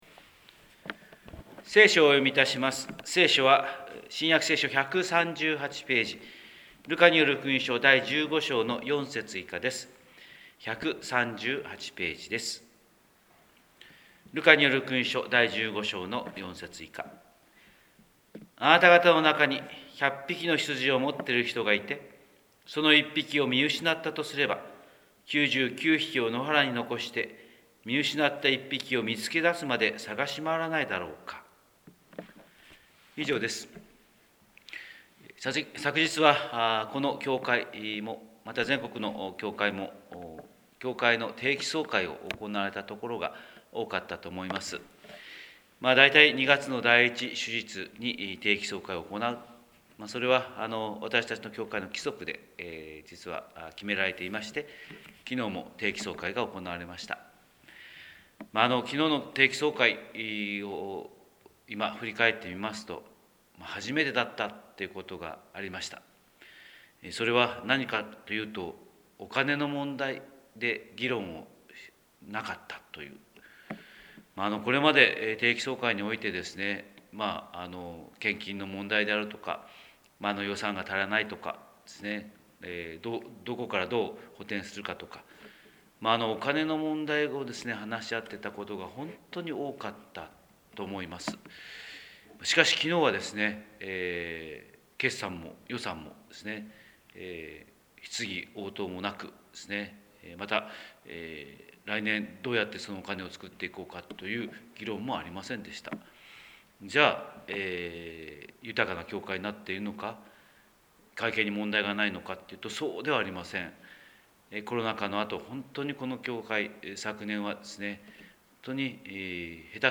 神様の色鉛筆（音声説教）: 広島教会朝礼拝250203
広島教会朝礼拝250203「総会が終わって」